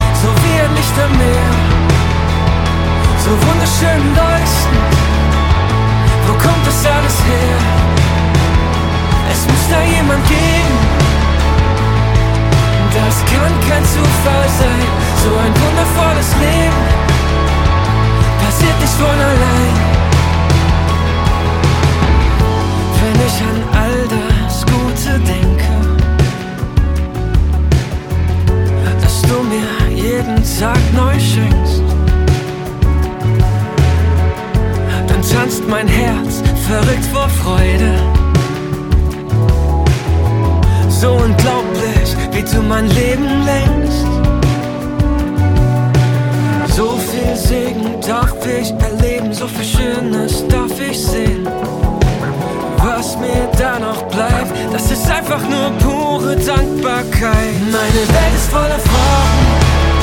Themenwelt Kunst / Musik / Theater Musik Pop / Rock
moderne Popmusik